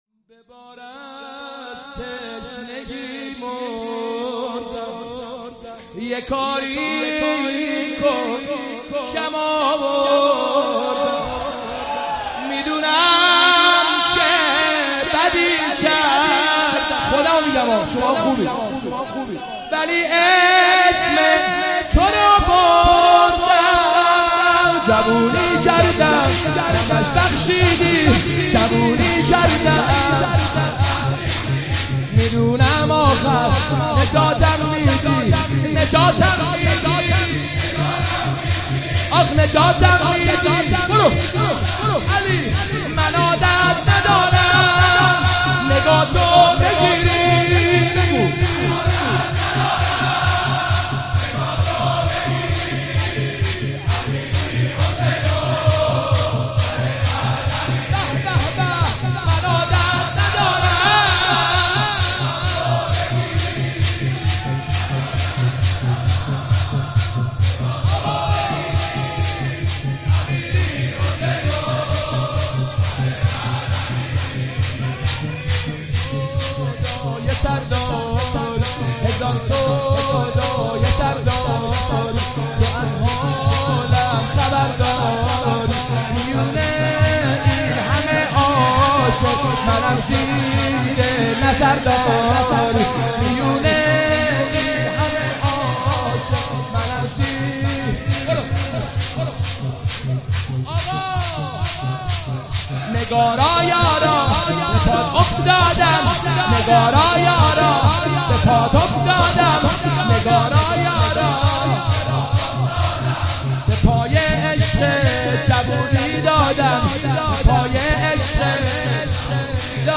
شب هفتم محرم۹۷ هیئت روضة‌ العباس
شور